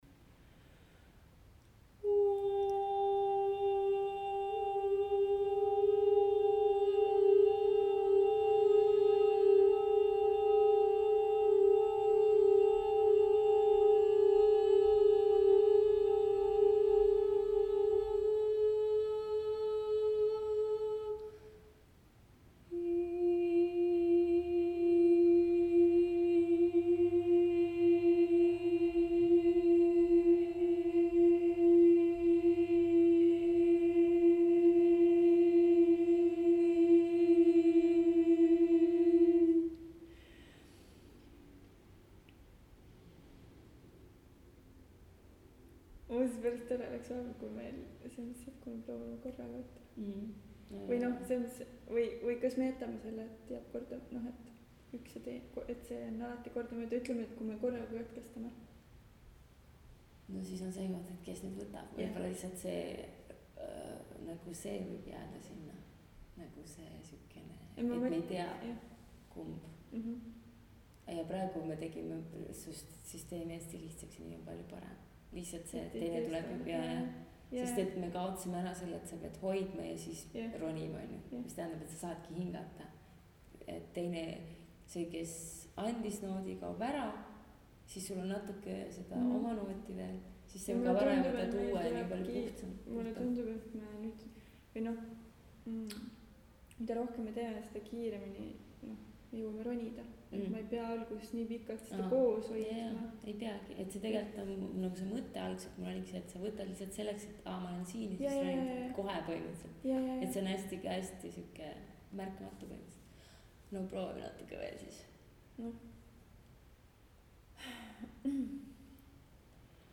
Voice as a body part.
Here is a short audio excerpt of our working session on wind/hearing:
The-Northern-Wind-compressed.mp3